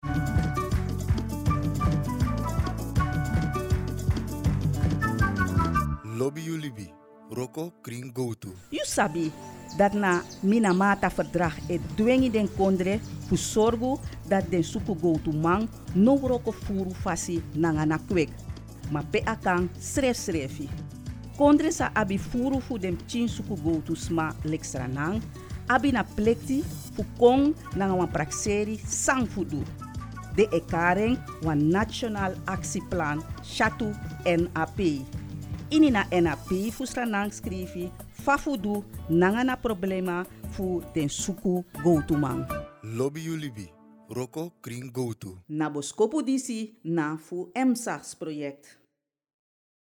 EMSAGS Sranan Radiospot 4